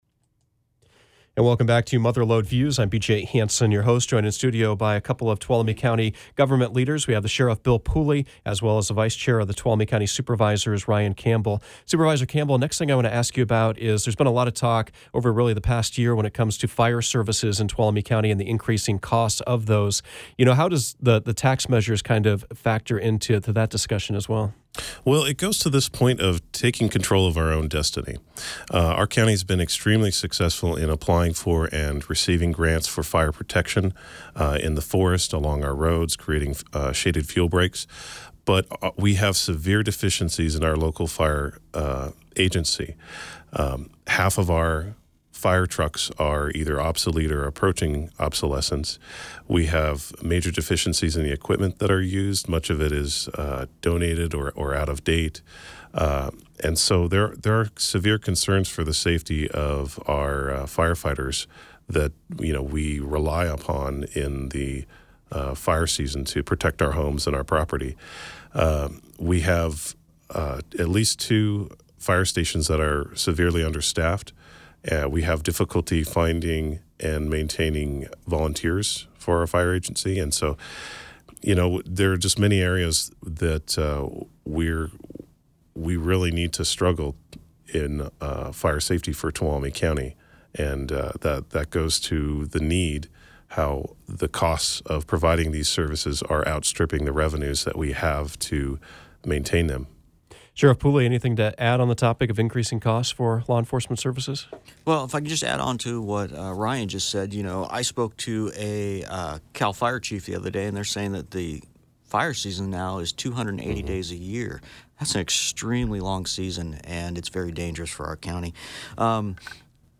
Mother Lode Views featured a conversation about the status of Tuolumne County's budget and ballot measures P and Q.
Mother Lode Views featured a conversation about the status of Tuolumne County’s budget and ballot measures P and Q. Guests included the Vice Chair of the Tuolumne County Supervisors, Ryan Campbell, and the Sheriff of Tuolumne County, Bill Pooley.